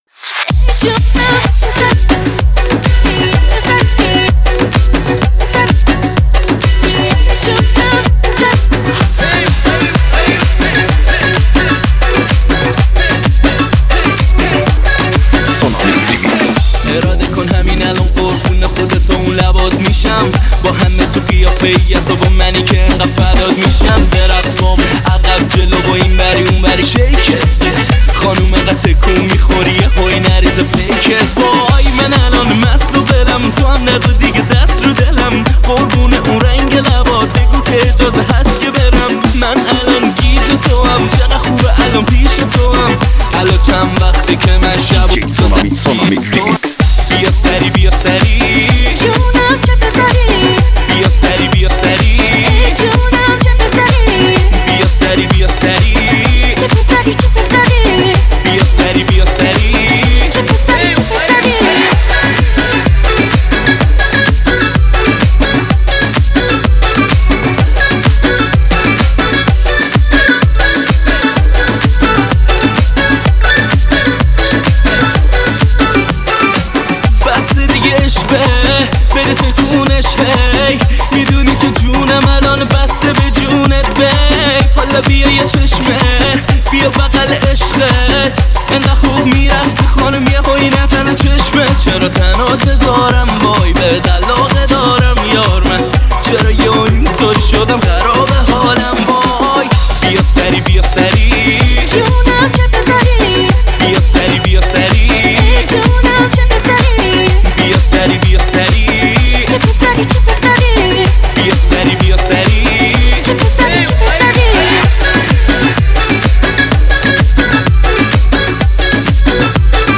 ریمیکس ایرانی